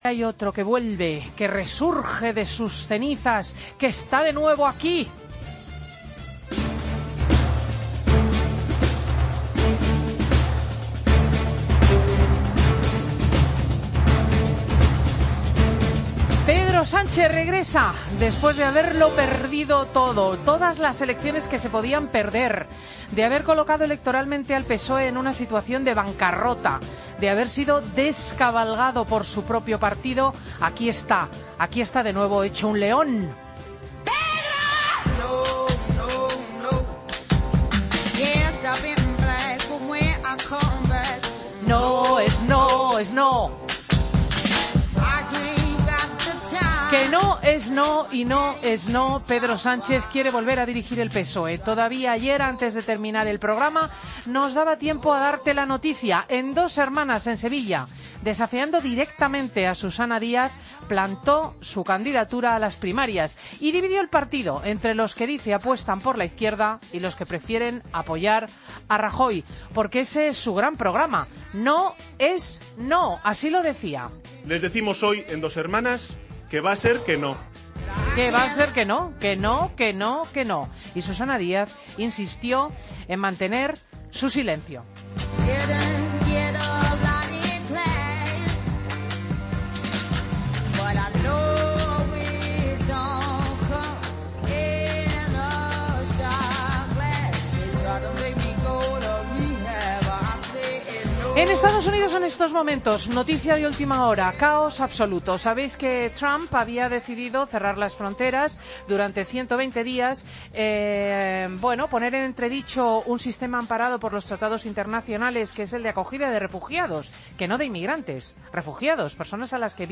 Monólogo de Cristina López Schlicting domingo 29 de enero 2017